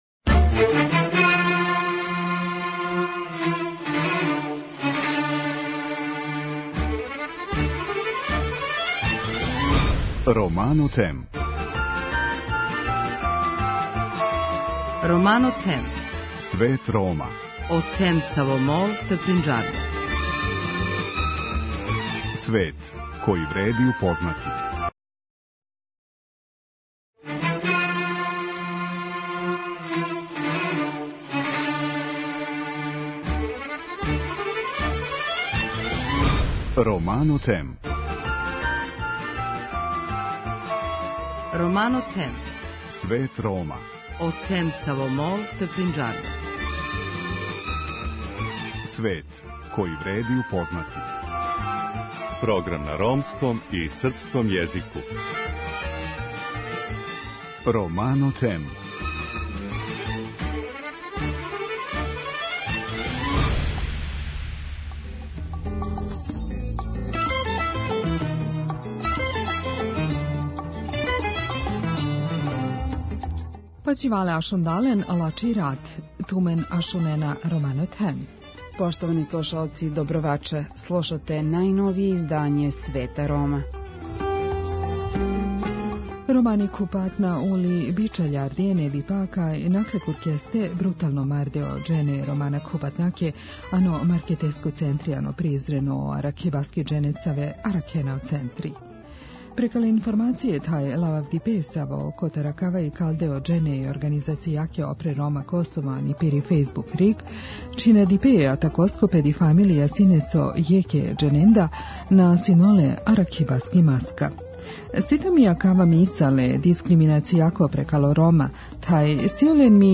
О немилом догађају за недељни Свет Рома говоре представници организације „Опре Рома".